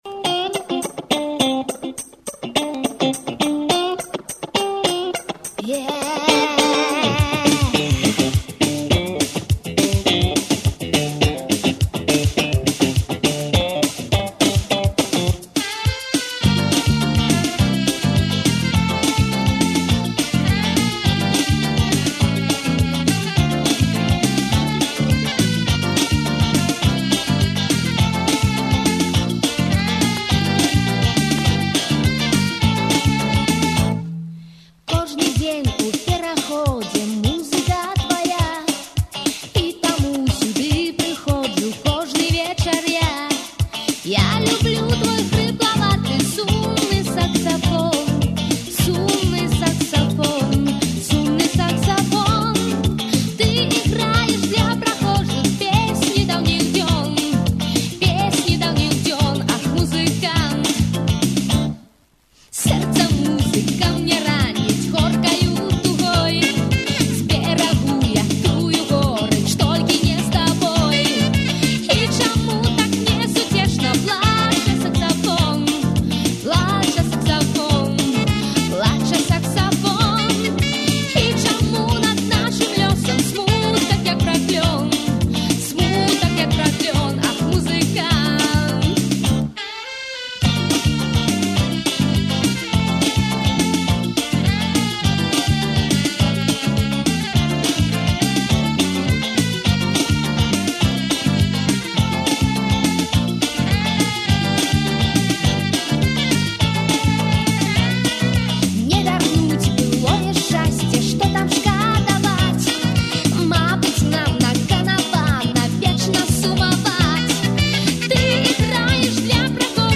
sumny_saksafon1.mp3